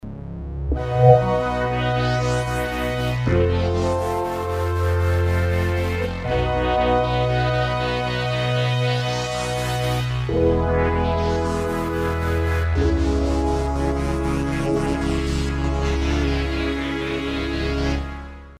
filter chords
Class: Synthesizer